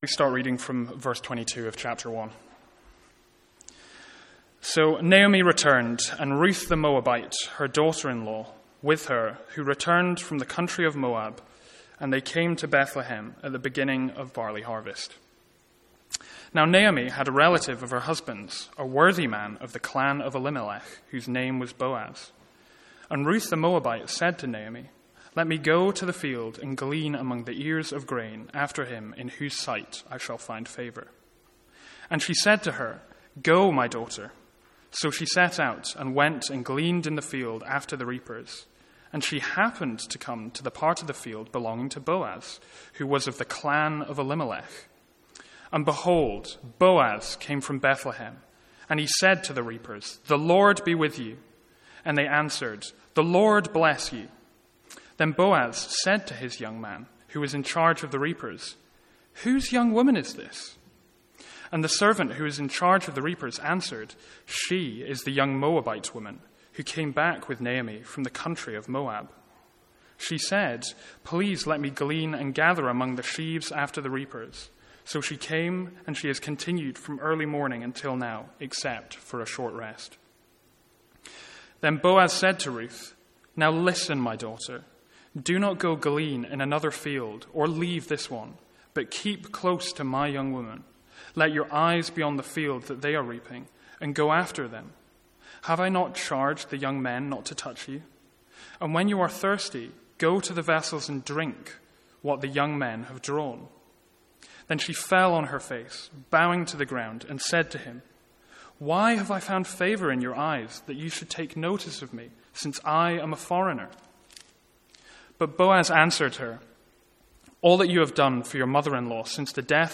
Sermons | St Andrews Free Church
From our evening series in the book of Ruth.